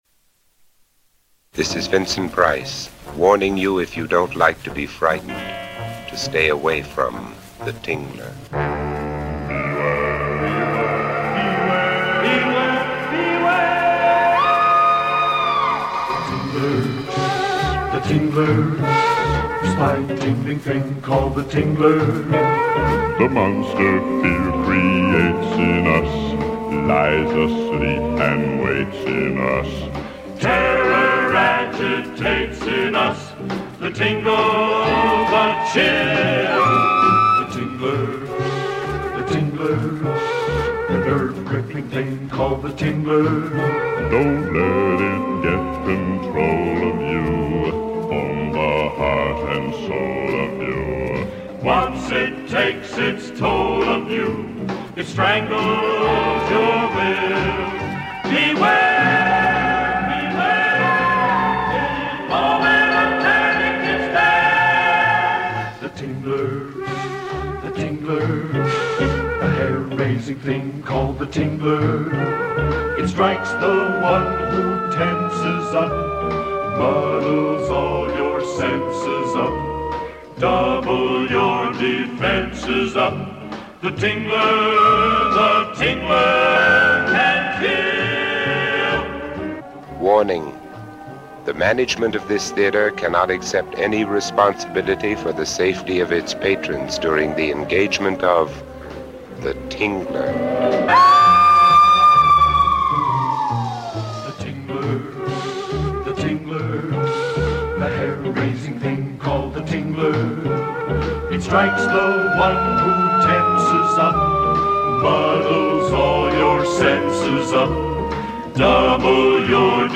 Theater lobby radio spot!
The-Tingler-Lobby-Spot-150-converted.mp3